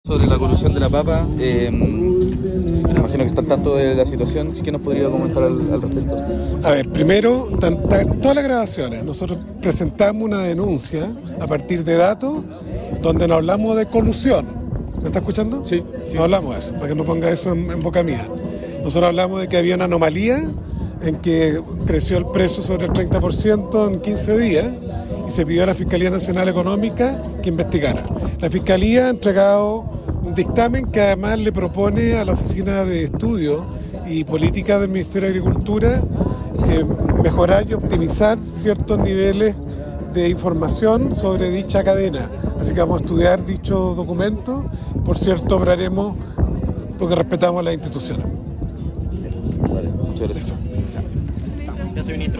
Este jueves, consultado por los medios de comunicación, el ministro de Agricultura -con evidente molestia- descartó haber hablado de colusión en el negocio de la papa.